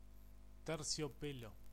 Ääntäminen
UK/US: IPA : /fliːs/